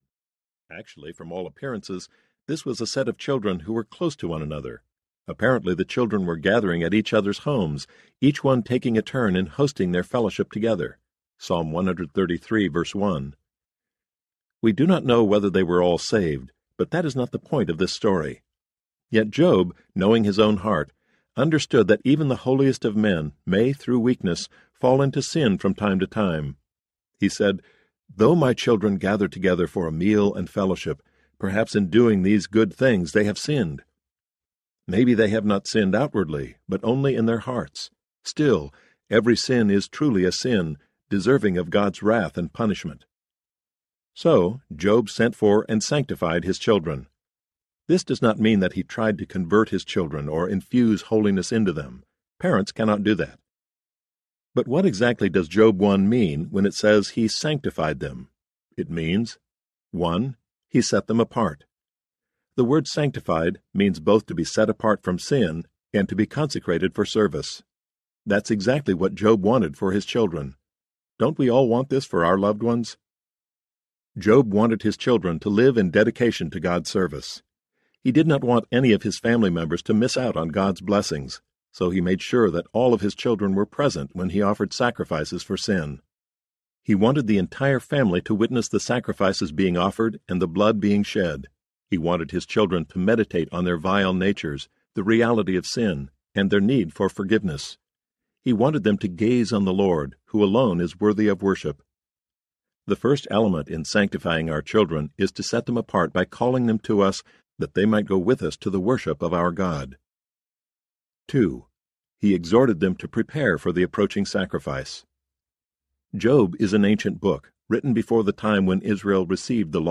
Parenting by God’s Promises Audiobook
10.1 Hrs. – Unabridged